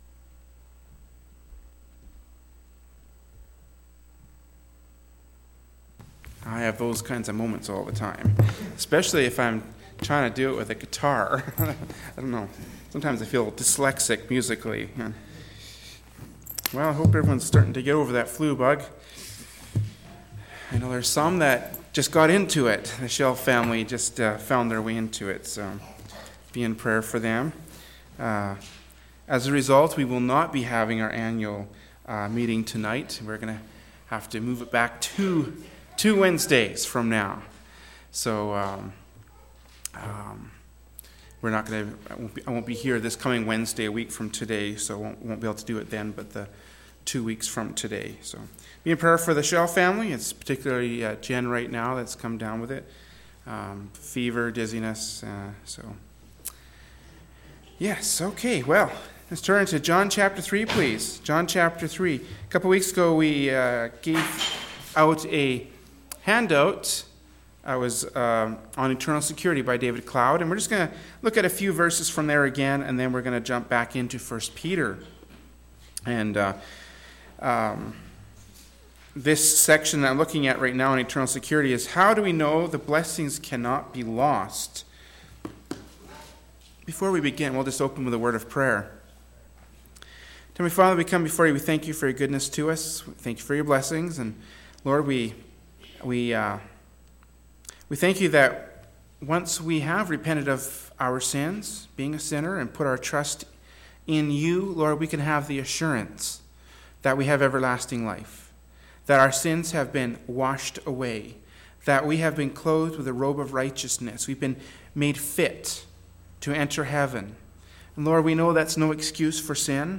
“1st Peter 1:4-6” from Wednesday Evening Service by Berean Baptist Church.